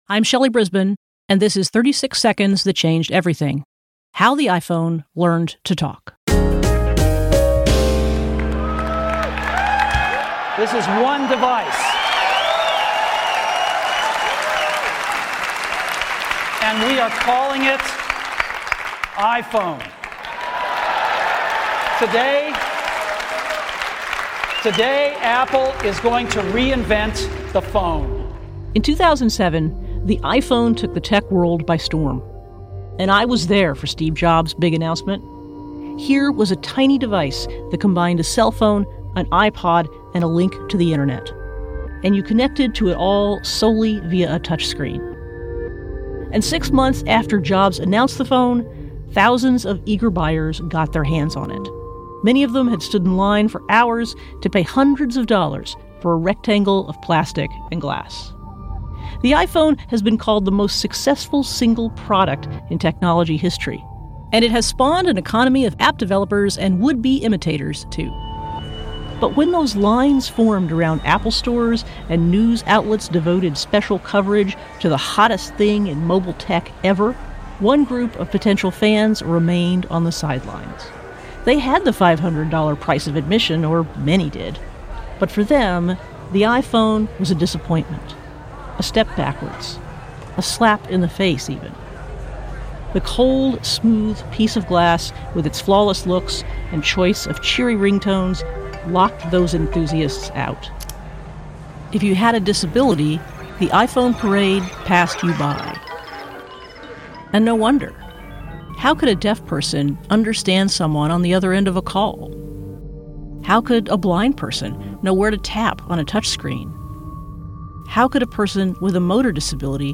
audio documentary